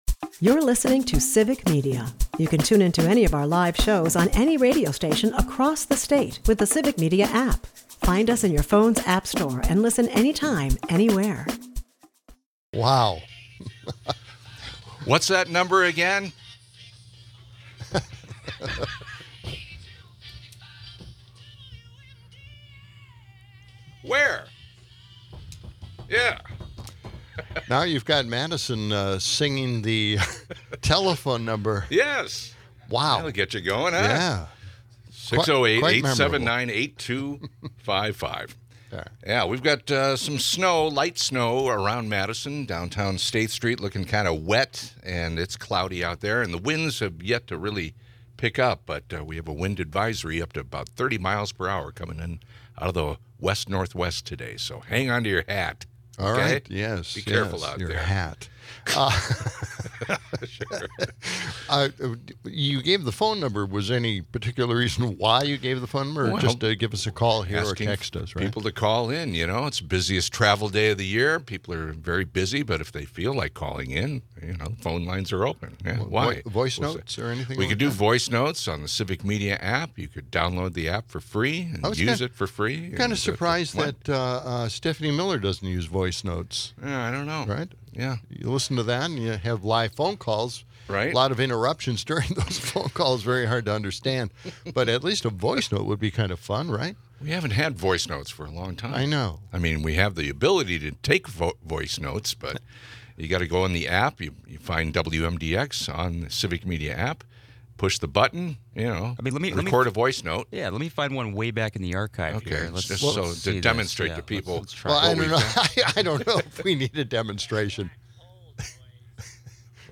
Amidst Madison's snowy backdrop, the episode dives into the ongoing healthcare debate, criticizing Republican opposition to ACA subsidies as costs surge and red states remain reliant on them. The show also takes a nostalgic detour into the decline of craft breweries, lamenting the closure of iconic names like Rogue Ales. The hosts banter about local events, give away tickets, and poke fun at the irony of government efficiency cuts.